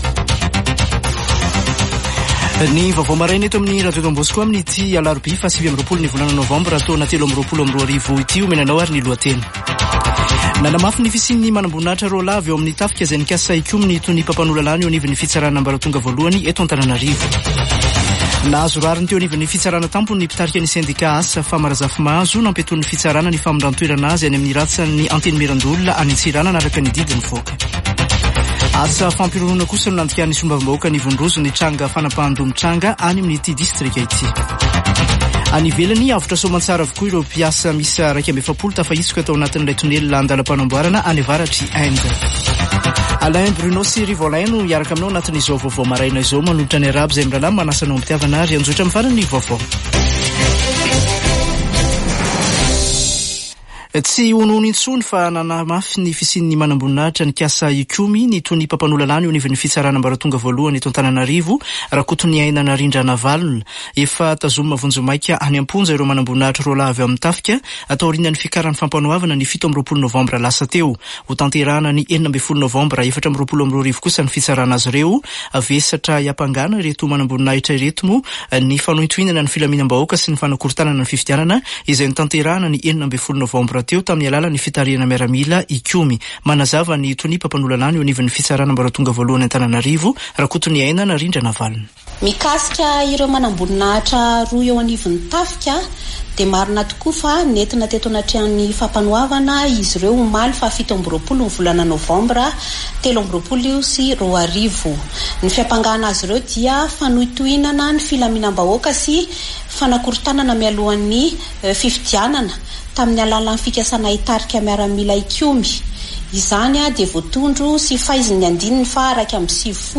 [Vaovao maraina] Alarobia 29 nôvambra 2023